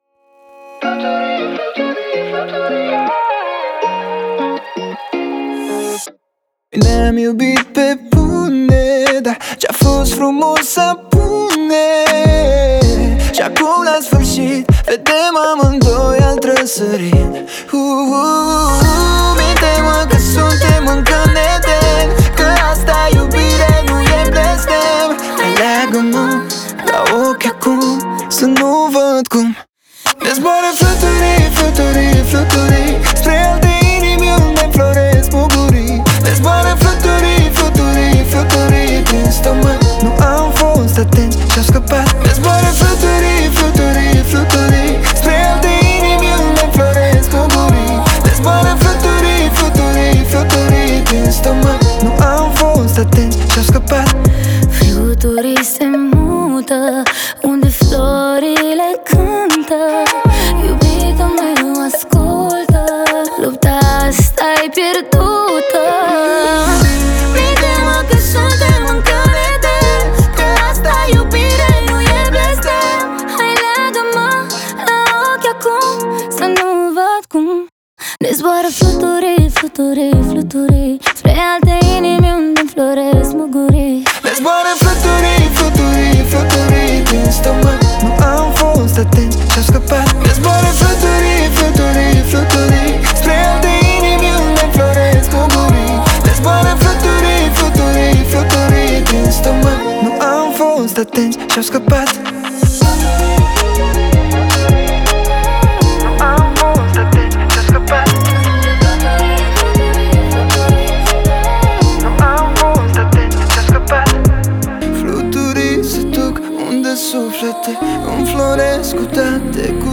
это яркая и мелодичная композиция в жанре поп